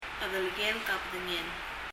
chedelekelek a bedengel [? əðələ(g)él(ə)k ə b(ə)ðəŋɛl]
発音を聴くと、「黒い」の chedelekelek は [? əðələgélə k] と聴こえます つまり、 後半の ・・・kelek の最初の k はルール通り [g]音になっていて、 その ke にアクセントがあり、最後の k は次の a とリエゾンして、 どちらかと言うと kab(e)dengel と聴こえるくらいです。